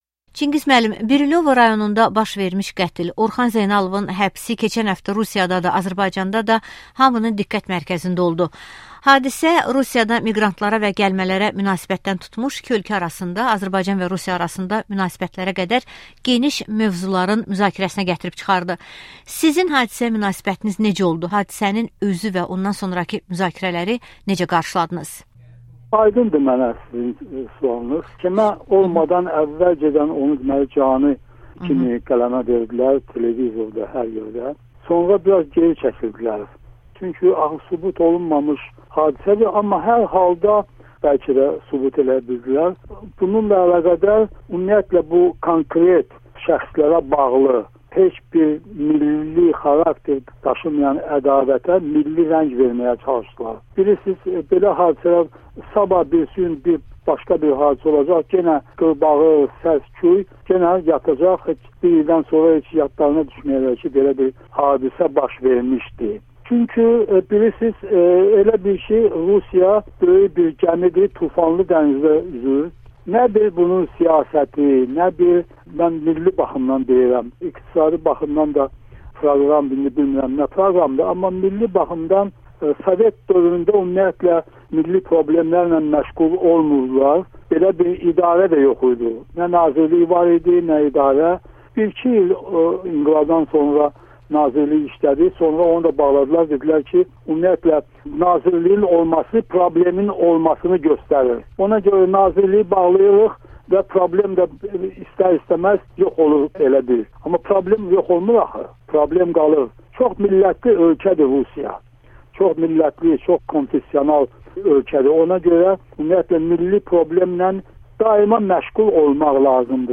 Çingiz Hüseynovla müsahibə